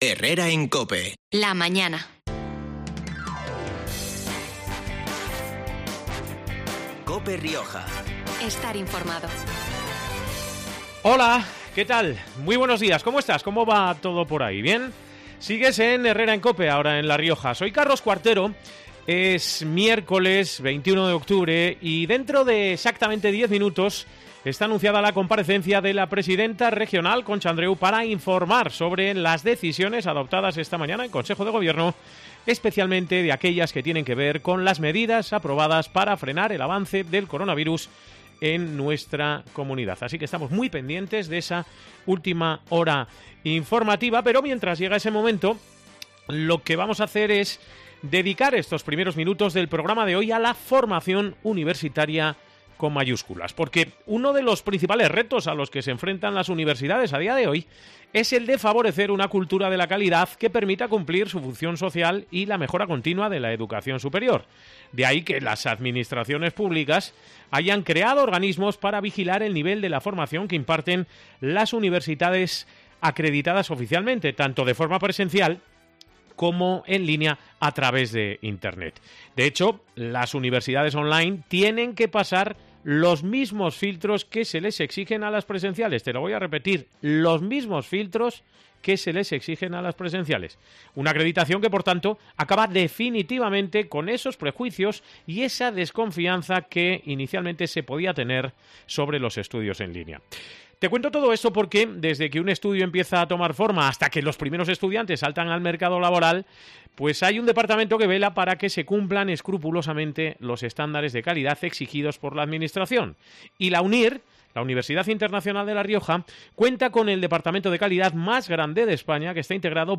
La UNIR equipara sus titulaciones a las de cualquier campus presencial Hemos hablado de ello con dos invitados.